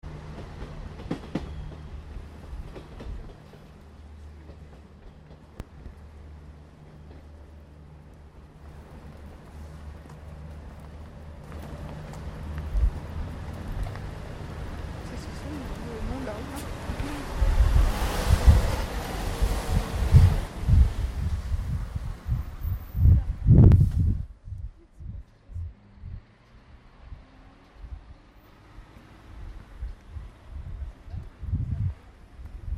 A proximité de la gare
traffic ferroviaire et routier.